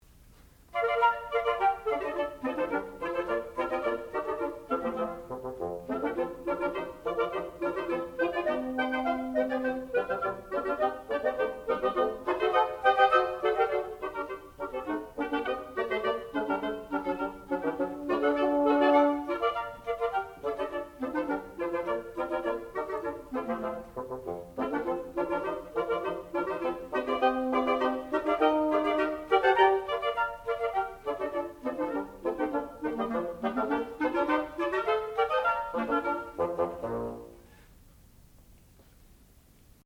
sound recording-musical
classical music
oboe
clarinet
flute